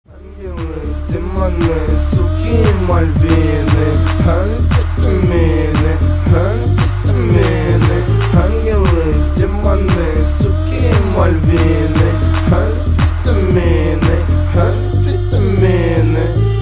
мужской вокал
русский рэп
low bass
Фрагмент демо-версии трека российской рэп-группы.